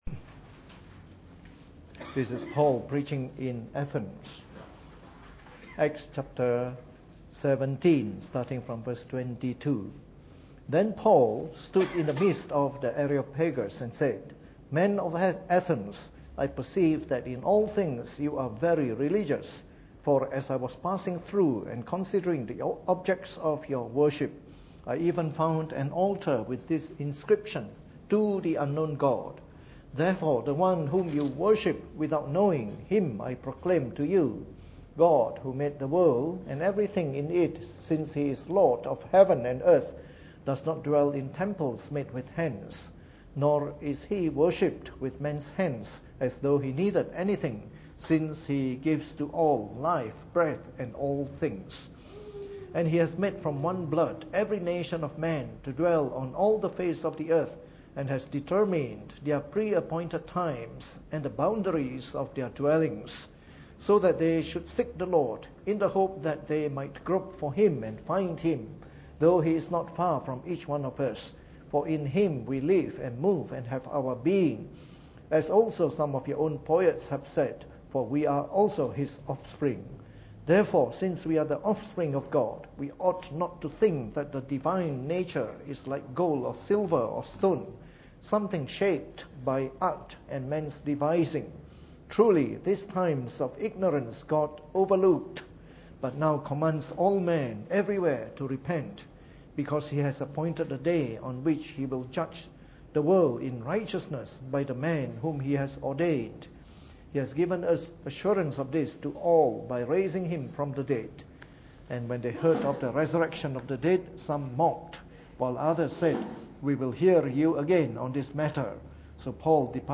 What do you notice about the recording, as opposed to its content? Preached on the 6th of April 2016 during the Bible Study, from our series on the Fundamentals of the Faith (following the 1689 Confession of Faith).